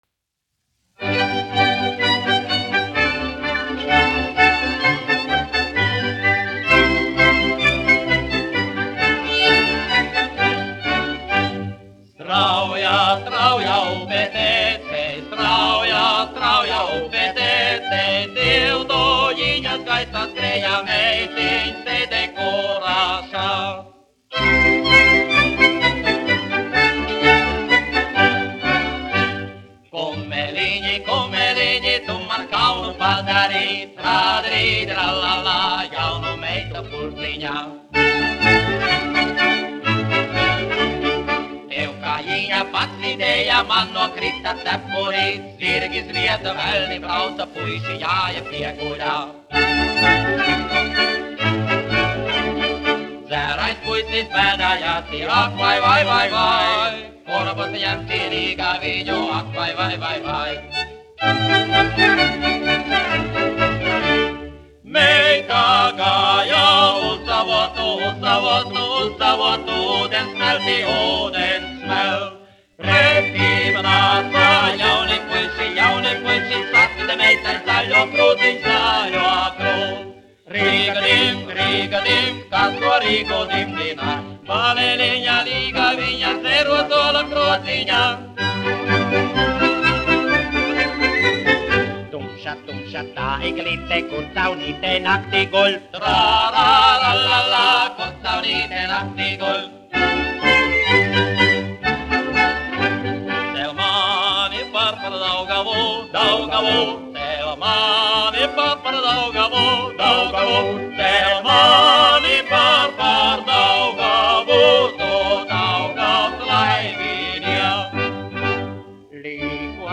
Brāļi Laivinieki (mūzikas grupa), izpildītājs
1 skpl. : analogs, 78 apgr/min, mono ; 25 cm
Latviešu tautasdziesmu aranžējumi
Marši
Skaņuplate